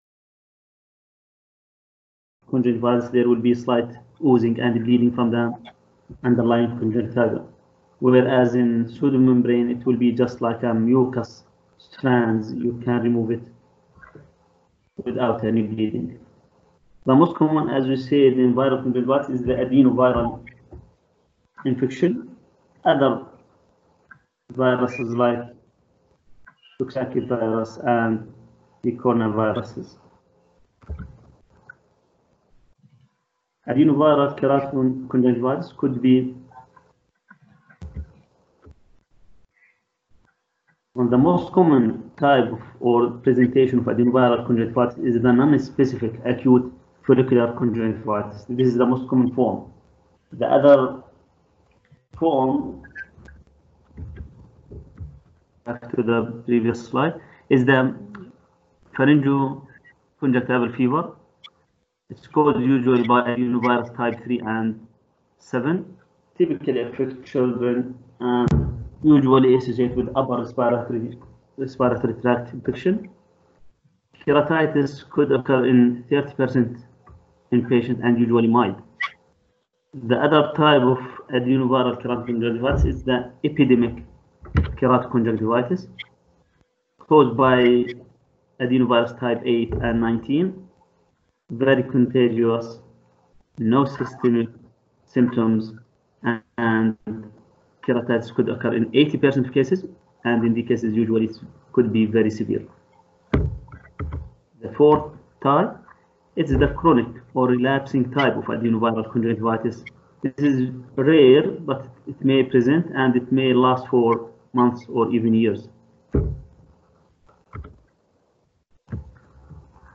conjunctiva lecture audio
conjuntiva-lecture.m4a